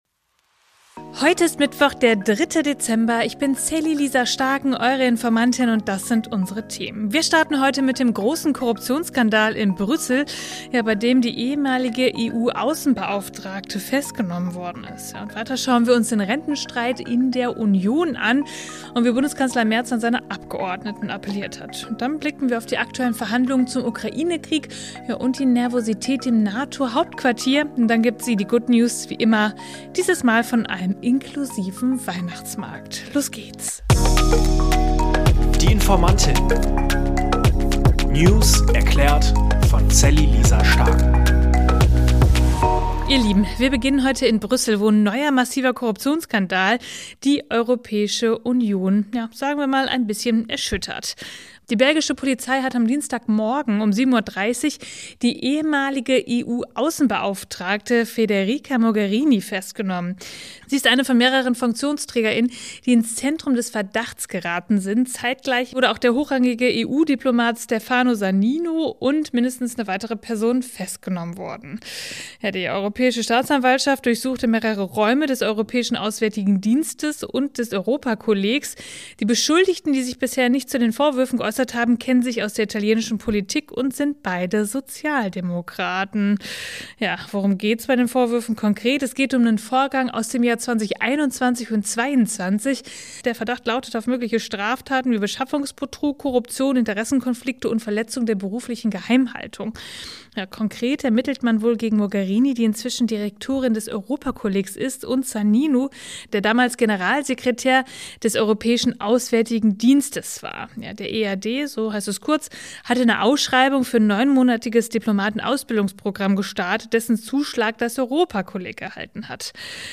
Mit ihrer ruhigen und verständlichen Art